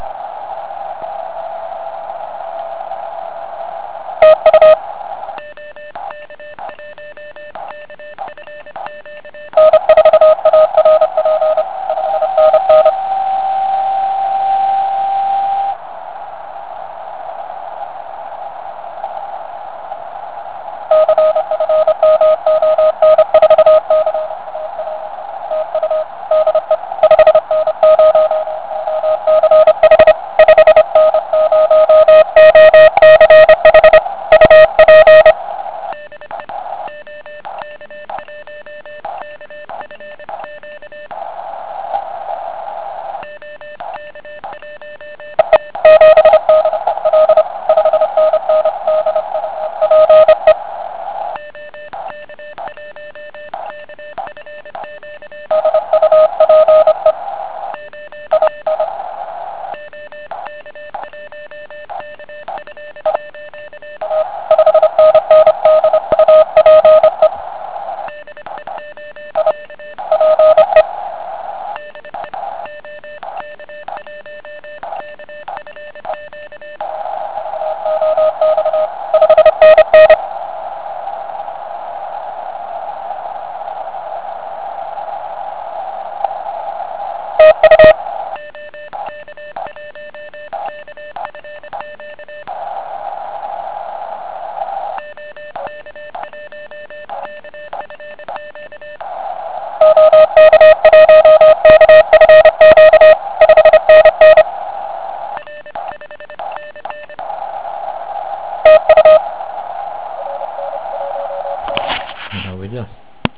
Je nově vybaveno vstupním atenuátorem, který v některých případech zvláště v pásmech 40 a 30m umožňuje vůbec něco slyšet.
Na můj vkus však rádio nepříjemně šumí.
Bohužel typický "Sound" nf filtru s OZ, je zde jasně patrný.